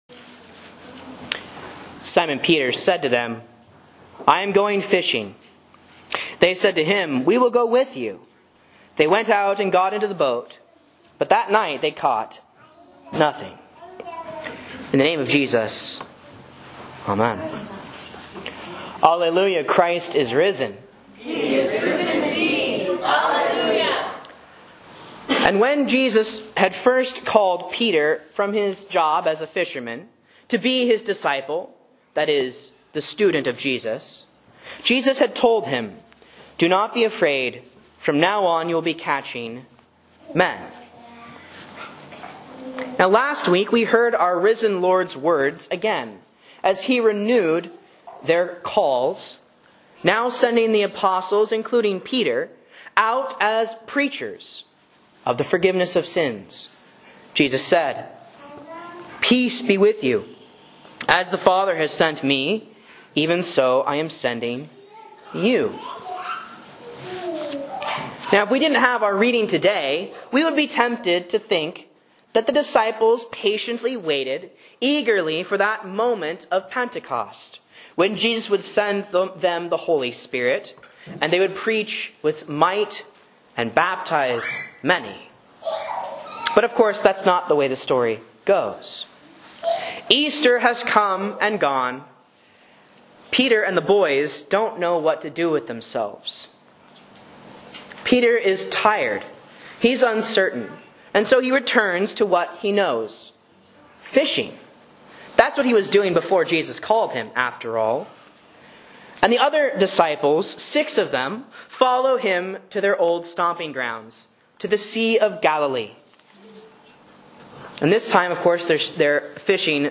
Sermons and Lessons from Faith Lutheran Church, Rogue River, OR
A Sermon on John 21.1-14 for Easter 3 (C)